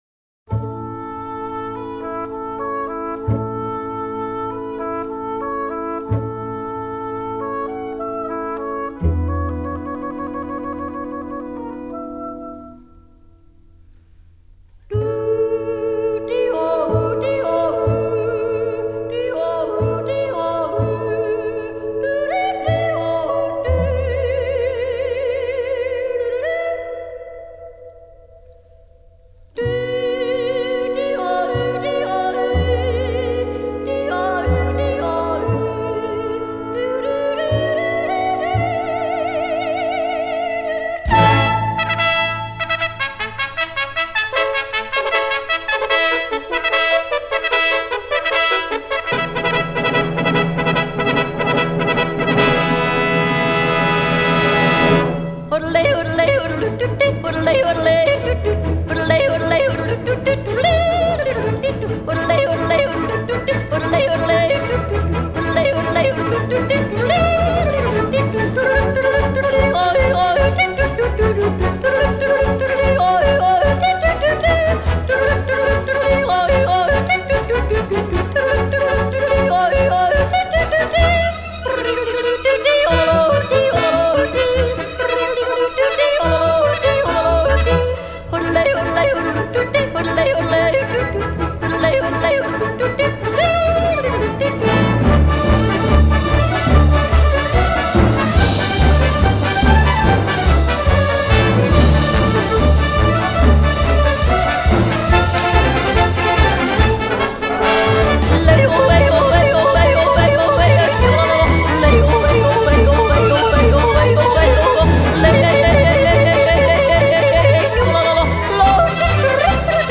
In yodle we trust